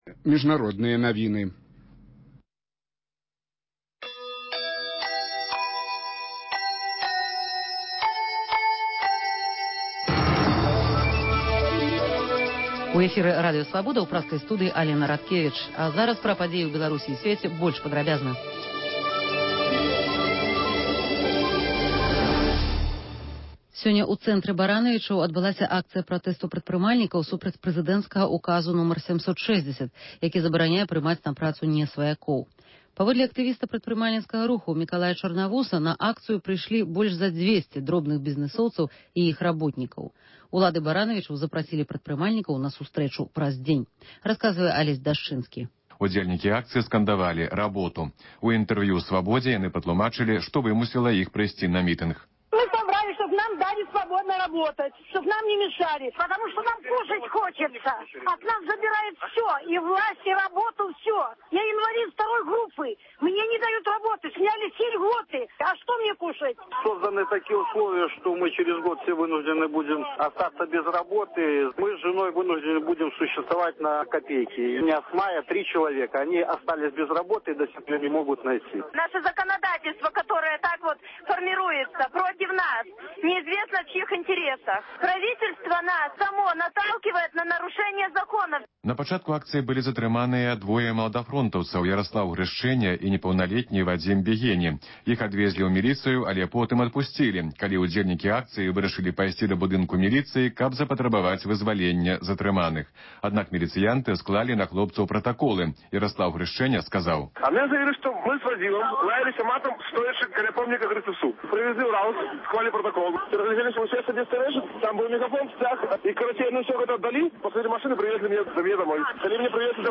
Паведамленьні карэспандэнтаў "Свабоды", званкі слухачоў, апытаньні на вуліцах беларускіх гарадоў і мястэчак.